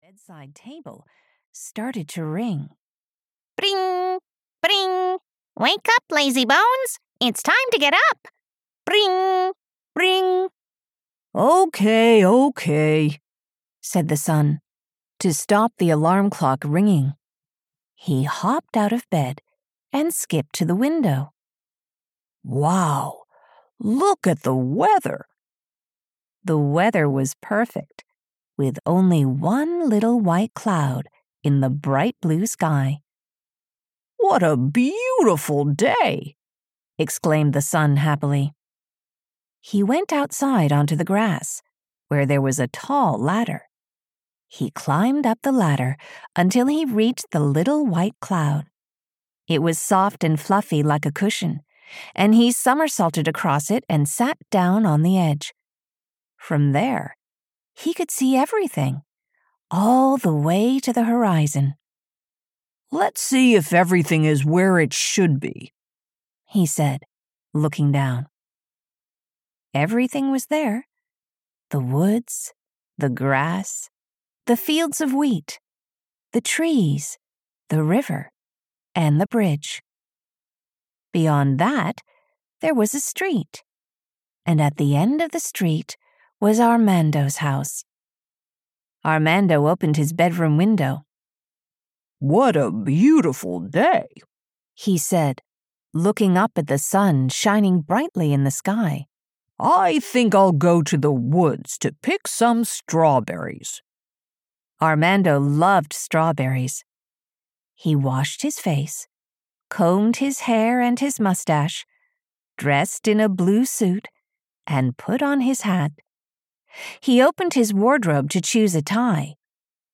Ukázka z knihy
la-pimpa-storytime-collection-en-audiokniha